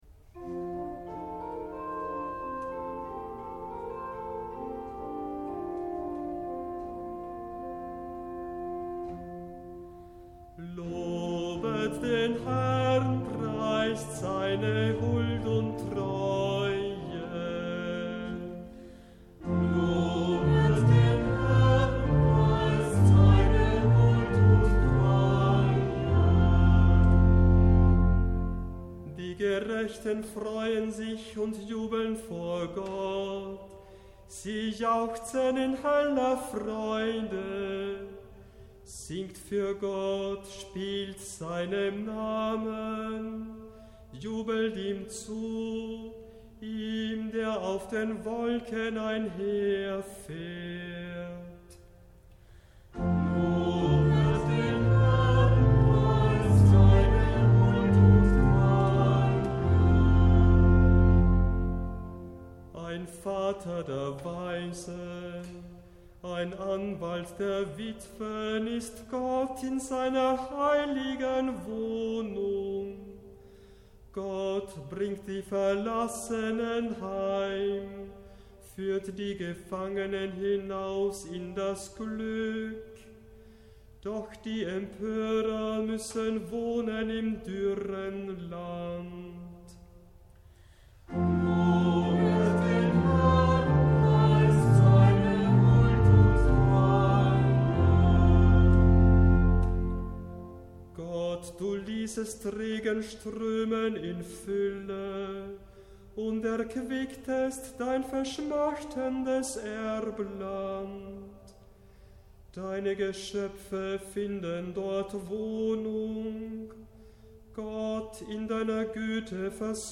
S. 283 Orgel
Kantor